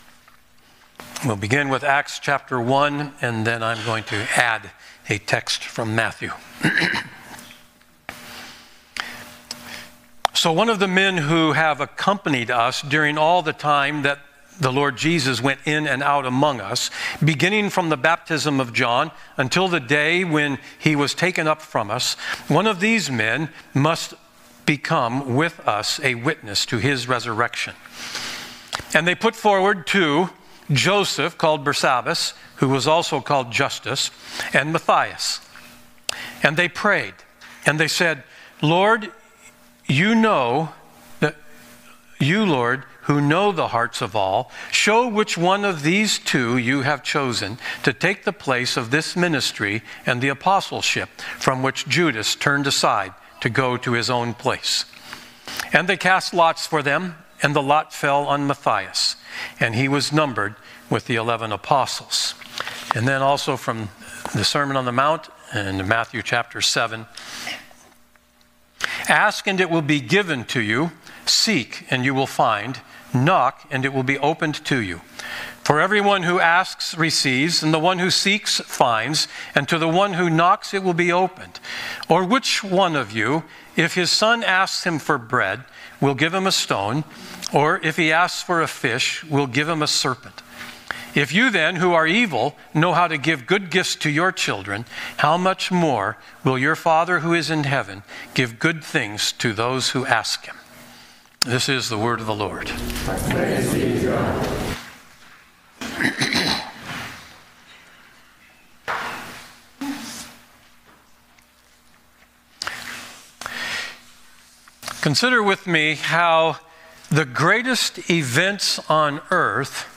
Download Sermon Notes Listen & Download Audio Series